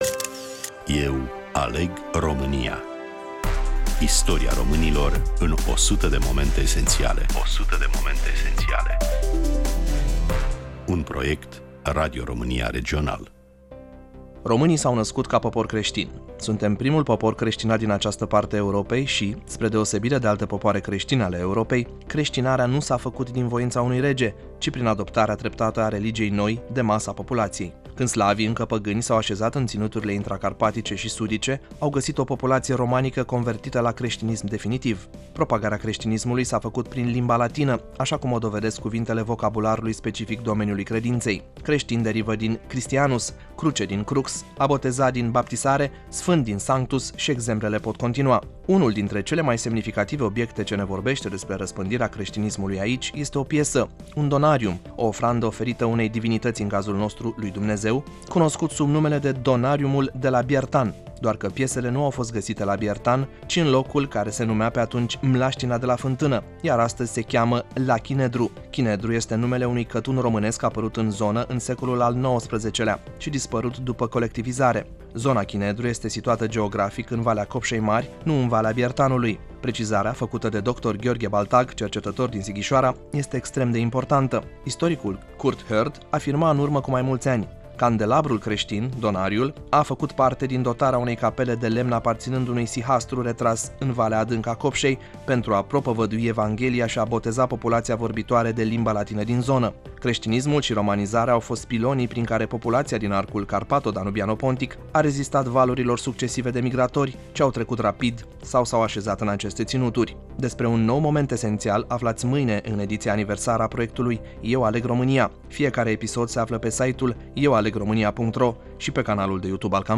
Prezentare